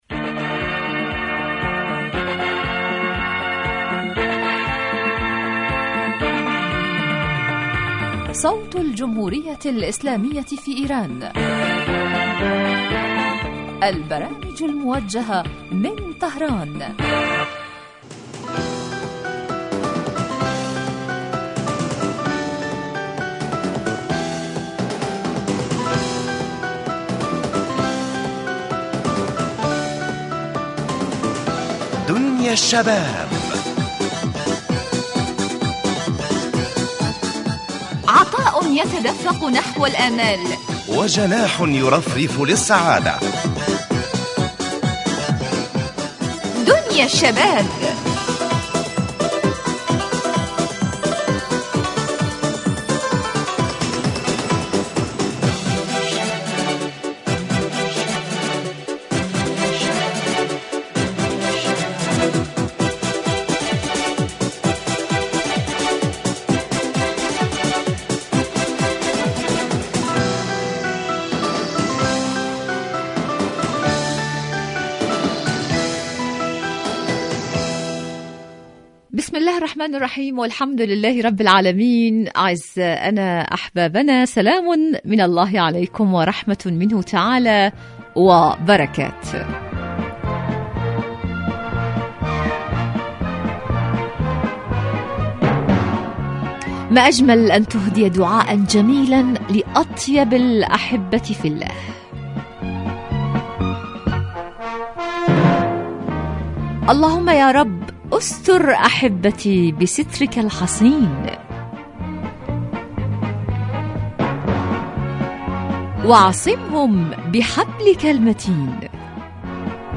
برنامج اجتماعي غني بما یستهوی الشباب من البلدان العربیة من مواضیع مجدیة و منوعة و خاصة ما یتعلق بقضایاهم الاجتماعیة وهواجسهم بالتحلیل والدراسة مباشرة علی الهواء